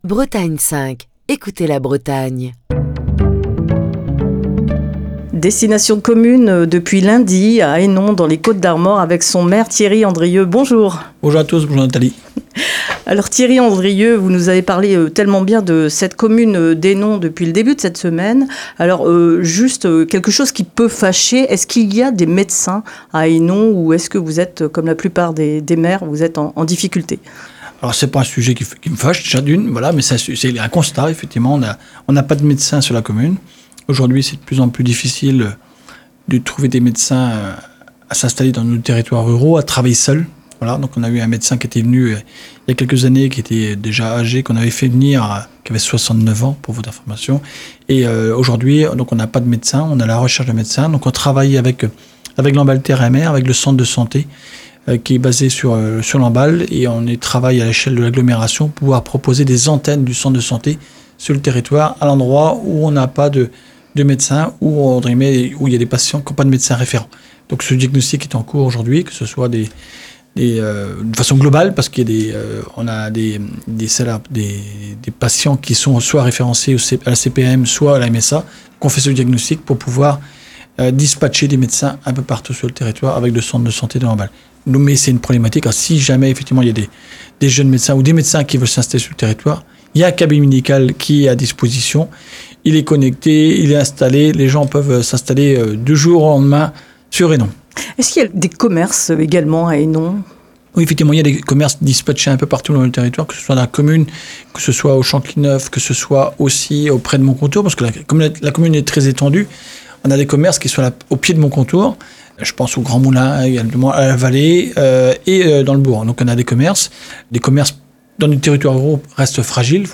Cette semaine, Destination commune était à Hénon, dans les Côtes-d'Armor.